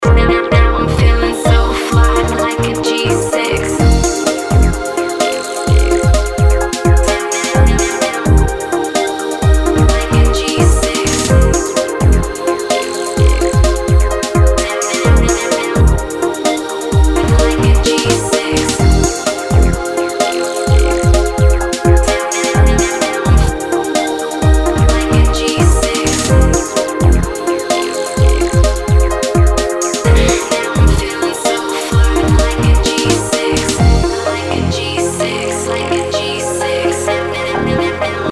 • Качество: 320, Stereo
remix
deep house
женский голос
спокойные
chillout
релакс
Чувственный чилаут